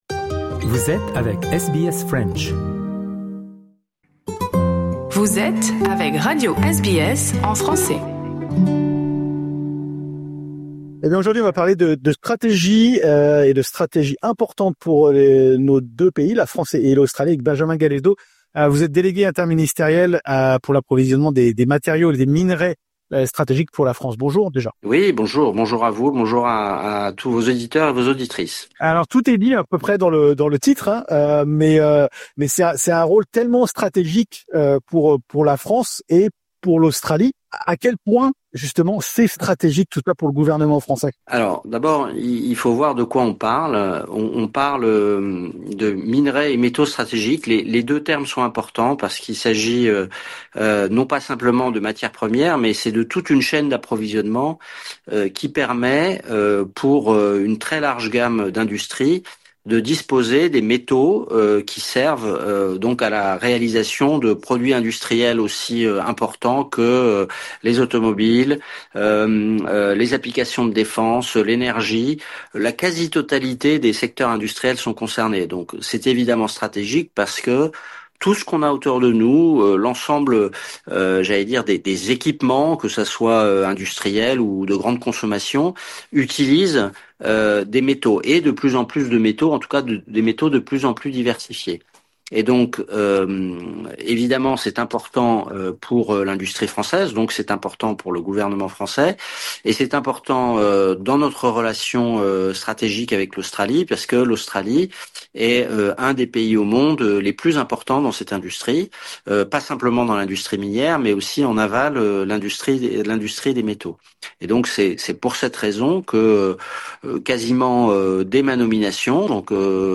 Enfin, l’entretien évoque les enjeux géopolitiques, notamment les répercussions de la guerre en Ukraine sur le marché mondial des métaux.